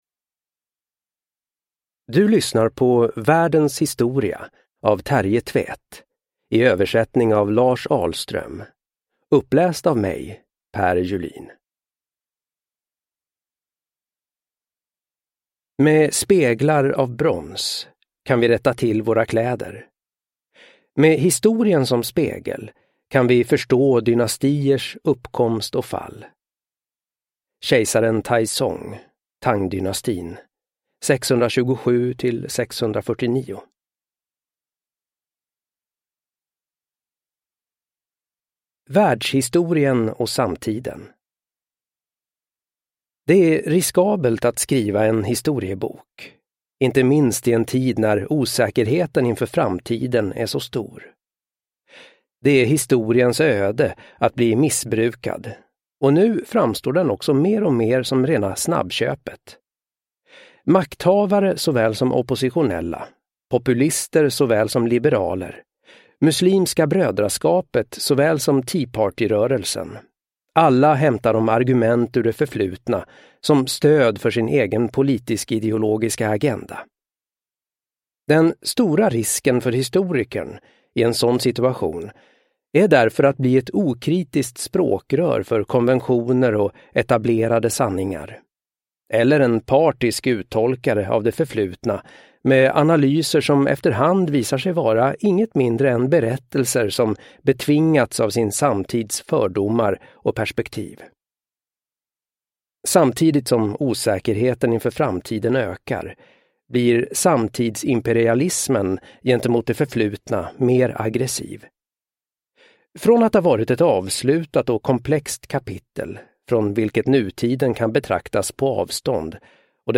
Världens historia : Med det förflutna som spegel – Ljudbok – Laddas ner